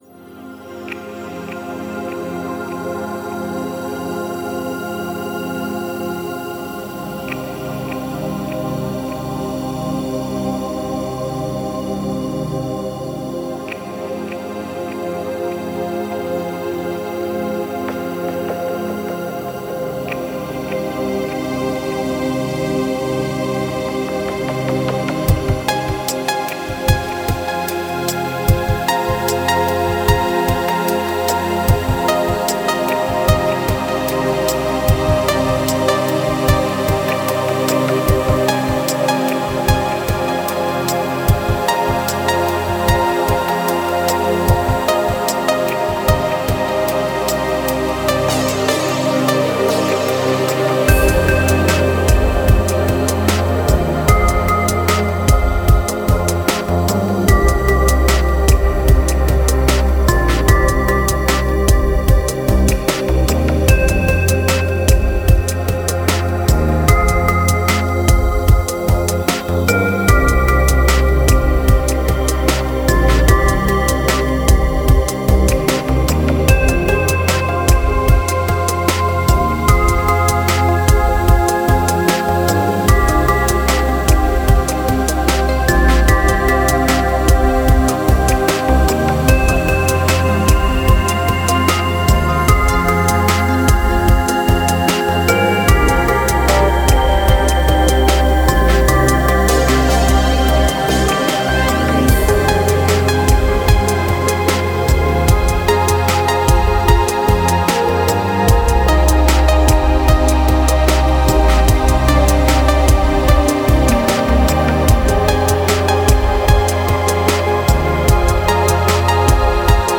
Downtempo, Lounge, Chill Out